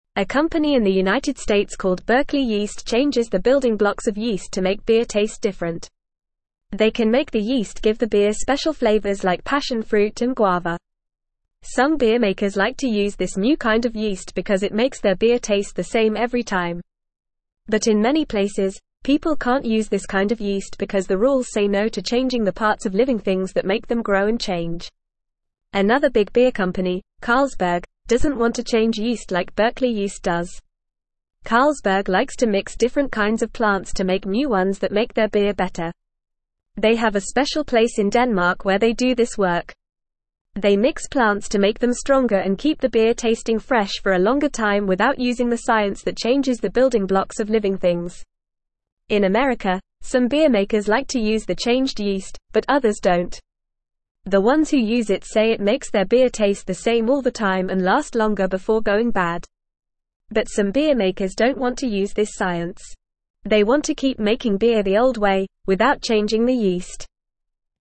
Fast
English-Newsroom-Lower-Intermediate-FAST-Reading-Changing-Yeast-to-Make-Beer-Taste-Different.mp3